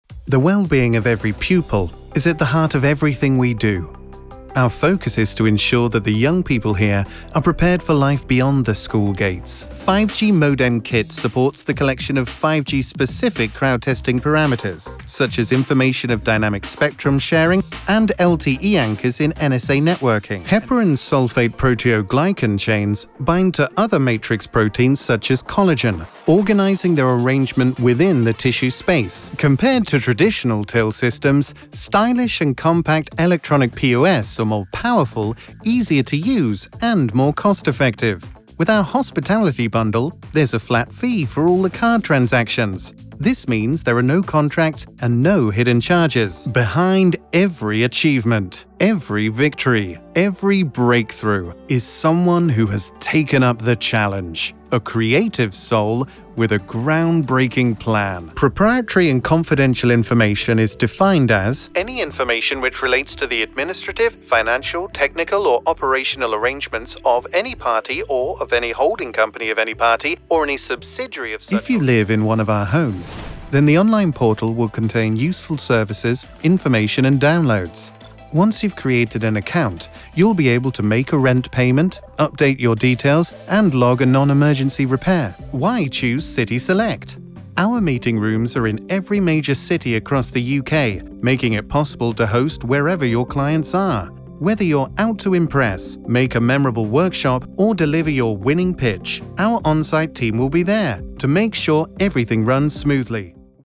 Reel Style: Corporate narration, eLearning
Voice Age: 25 – 50
Voice Type: Warm, Trustworthy, Authentic
Corporate narration